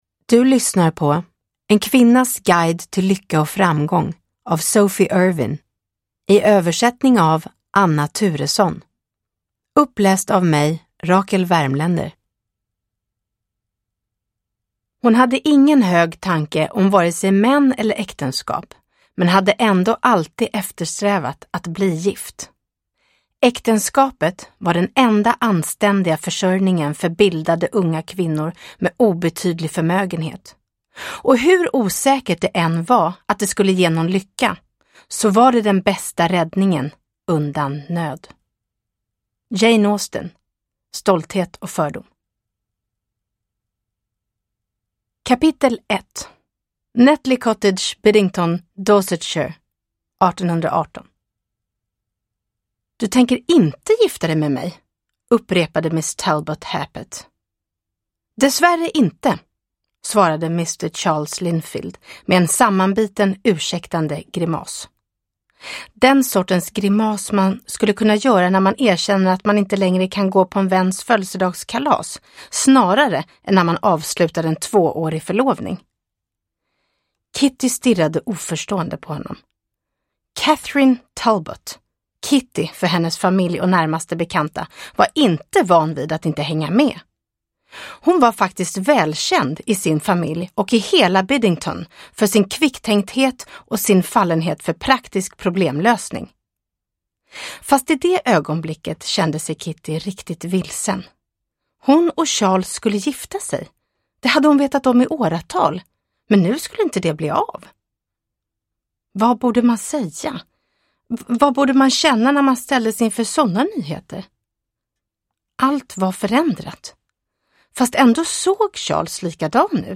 En kvinnas guide till lycka och framgång – Ljudbok – Laddas ner